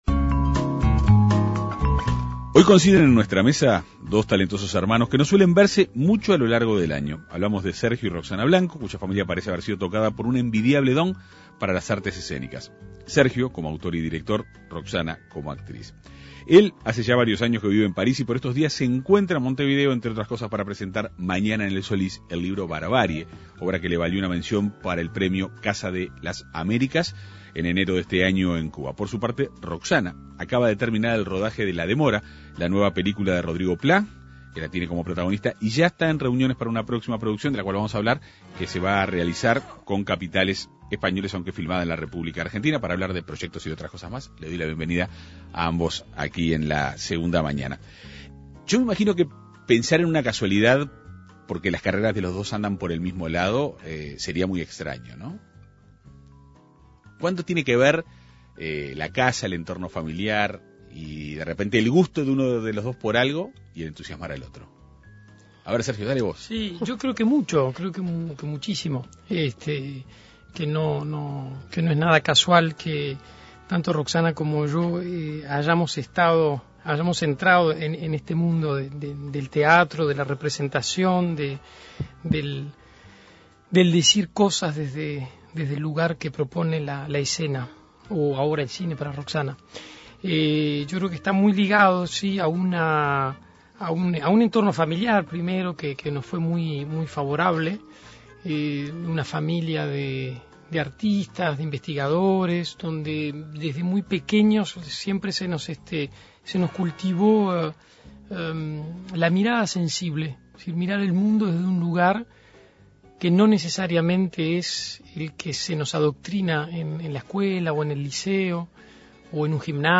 dialogaron en la Segunda Mañana de En Perspectiva.